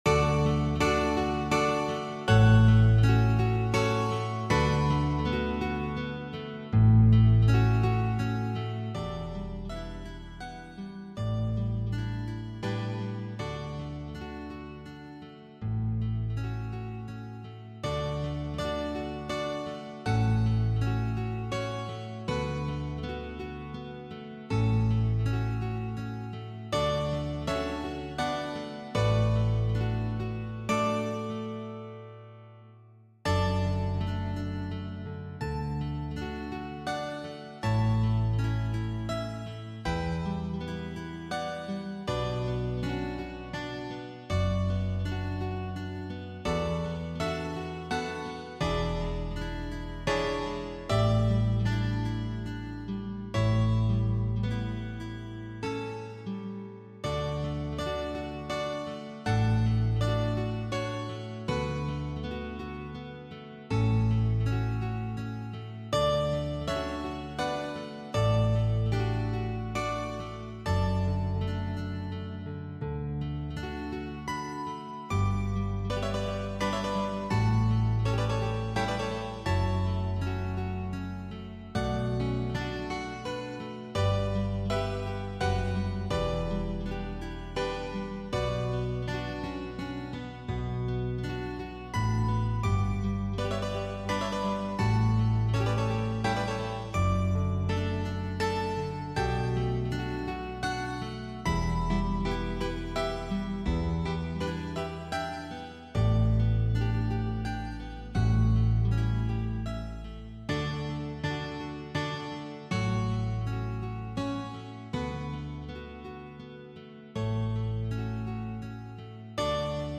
Zupforchester, ,
Besetzung: Zupforchester